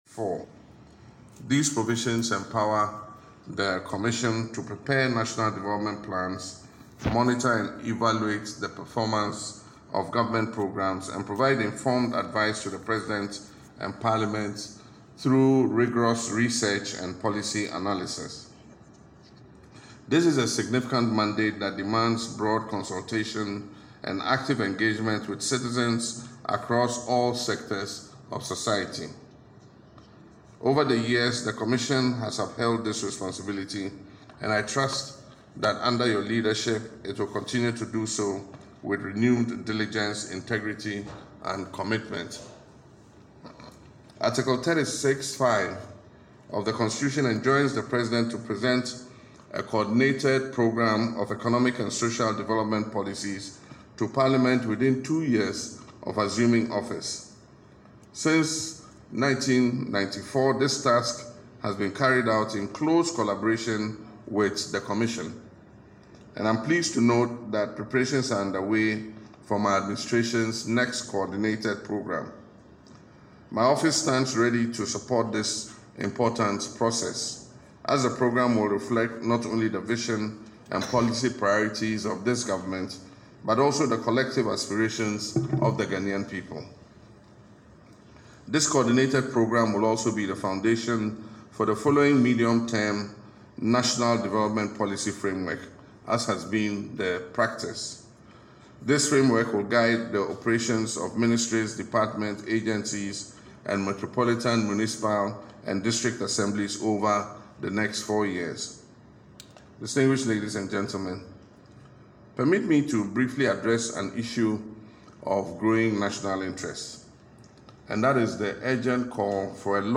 Speaking at the swearing-in ceremony of new NDPC held at the Jubilee House on Tuesday, 20th May, President Mahama underscored the importance of a unified national vision to guide Ghana’s development trajectory.